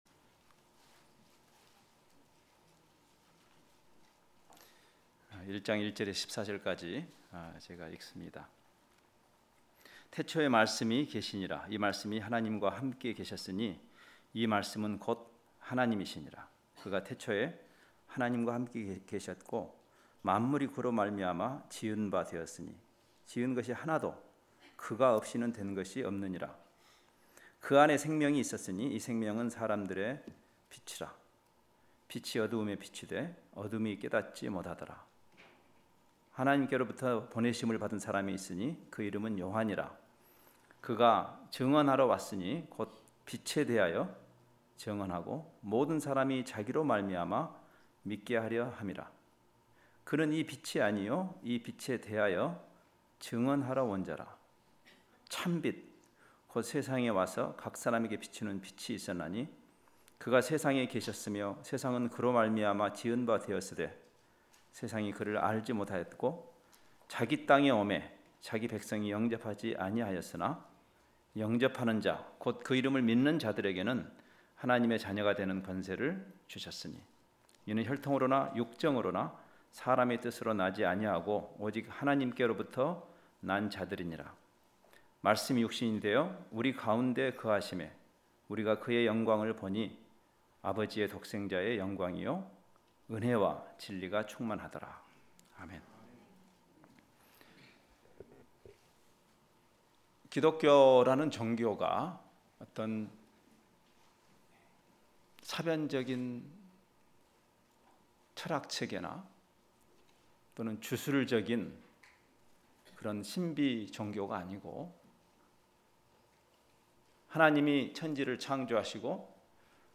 요한복음 1장 1-14절 관련 Tagged with 주일예배 , 특별집회